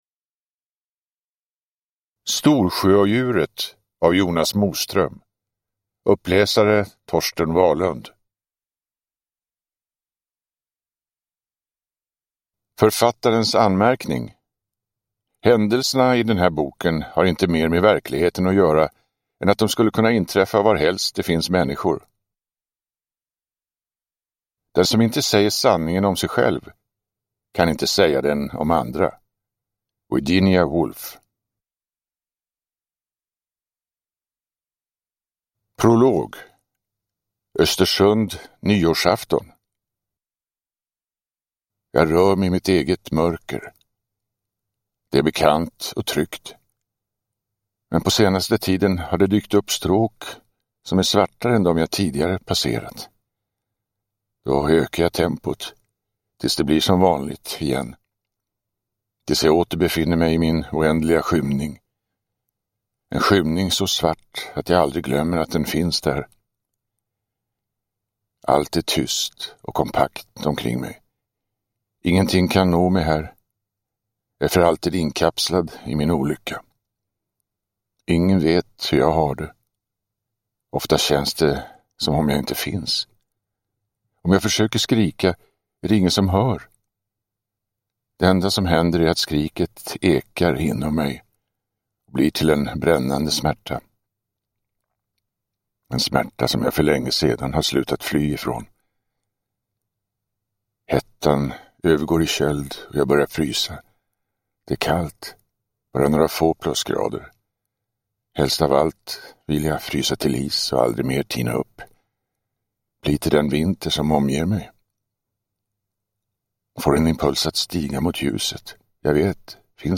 Storsjöodjuret – Ljudbok – Laddas ner
Uppläsare: Torsten Wahlund